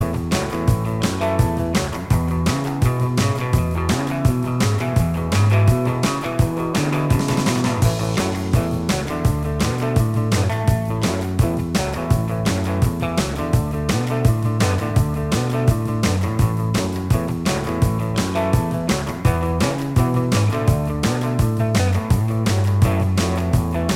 Minus Lead Guitar Rock 2:22 Buy £1.50